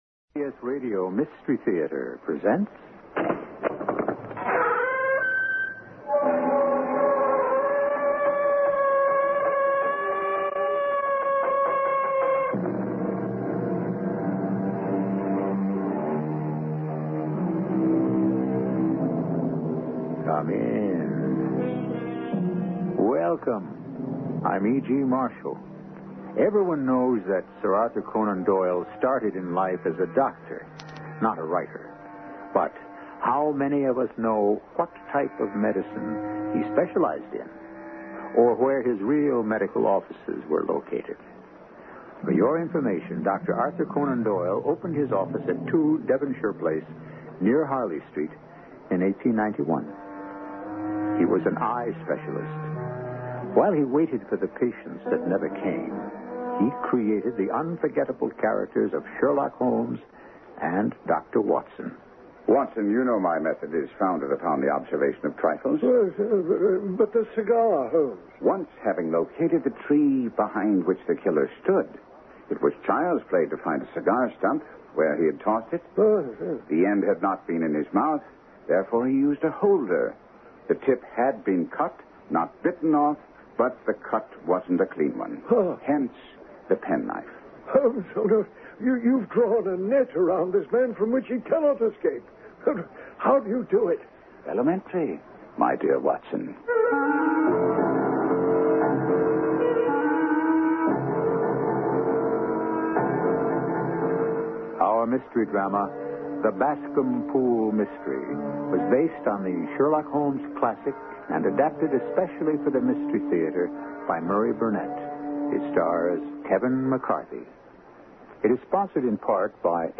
Radio Show Drama with Sherlock Holmes - The Boscombe Pool Mystery 1977